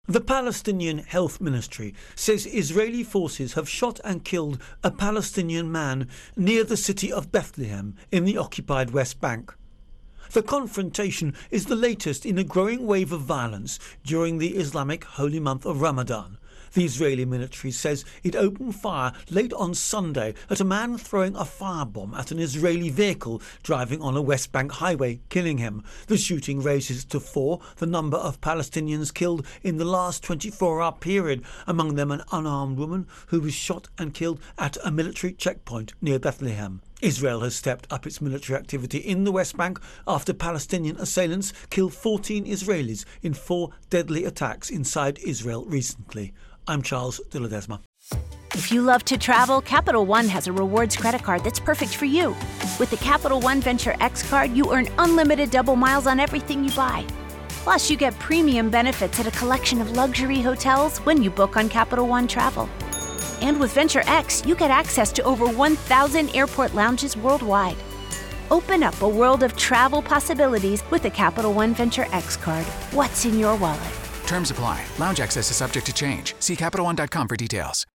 Israel Palestinian Shooting Intro and Voicer